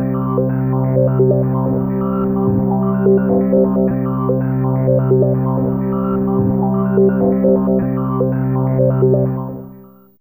Index of /m8-backup/M8/Samples/Fairlight CMI/IIe/27Effects4
SandHldC2.wav